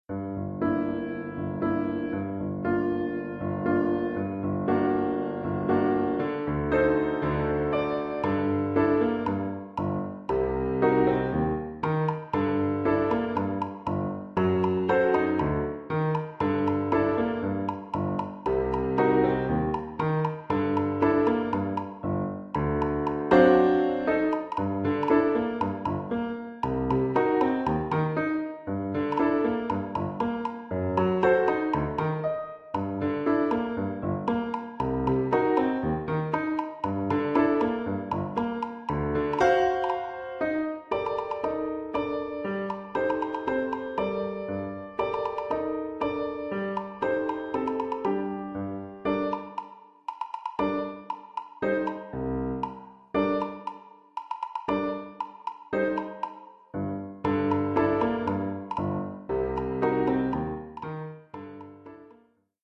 Oeuvre pour caisse claire et piano.